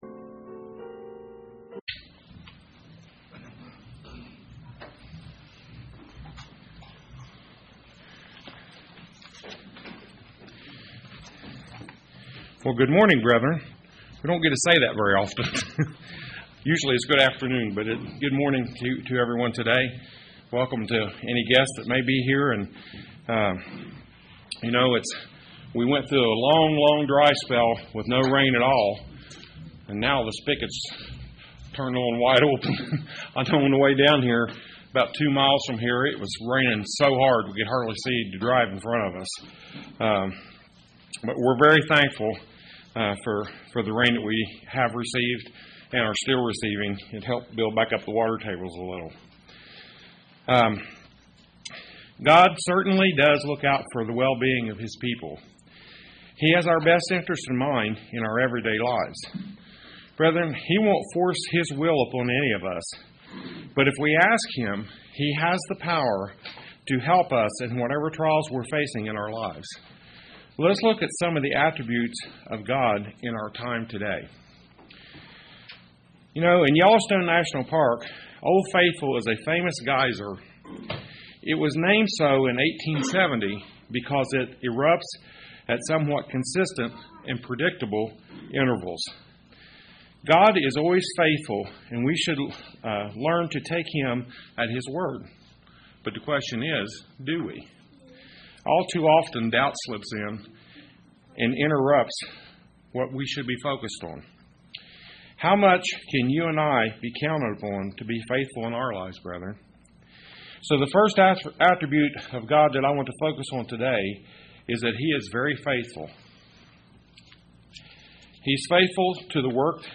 This sermon brings us to the true character of God. Will He stand by us?
Given in Paintsville, KY